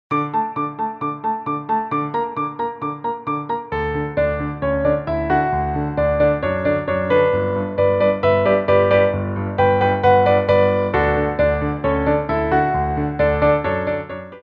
Piano Arrangements
Tendus
4/4 (16x8)